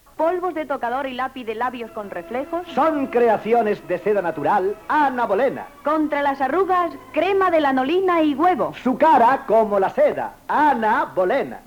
Publicitat de la crema Ana Bolena